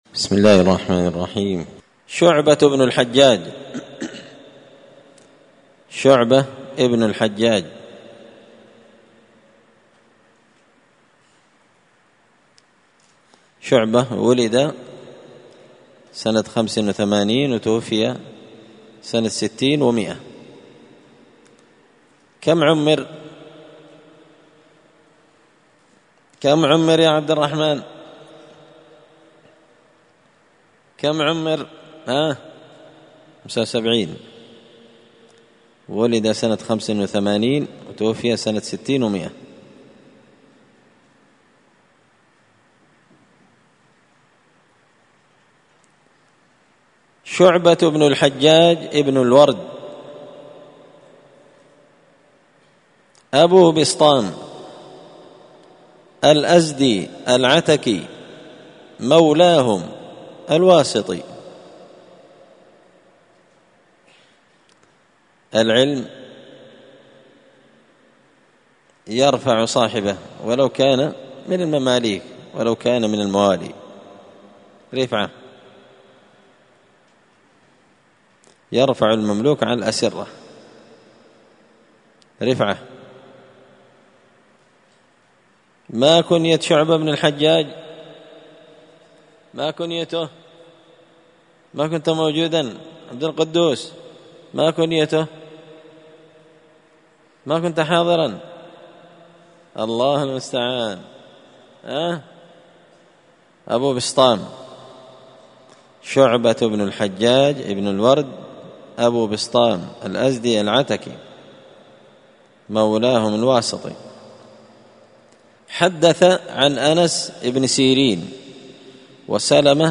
(الدرس (1) ترجمة شعبة ابن الحجاج)